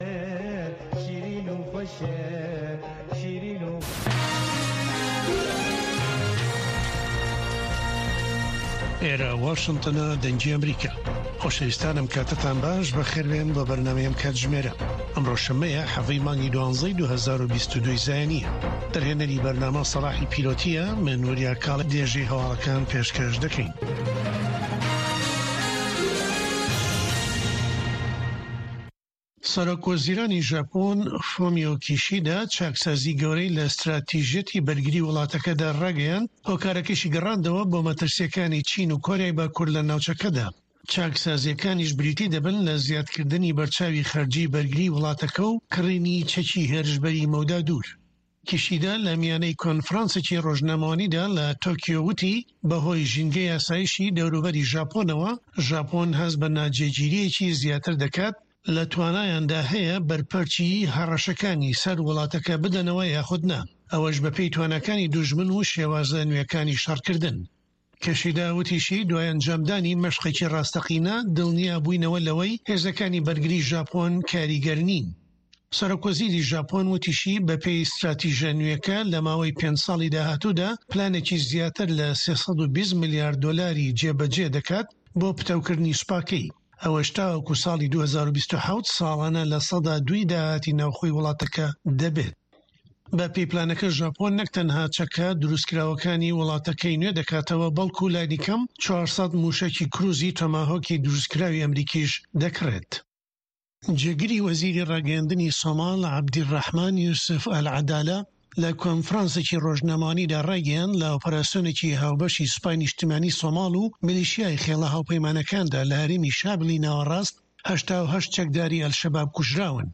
Nûçeyên 3’yê paşnîvro
Nûçeyên Cîhanê ji Dengê Amerîka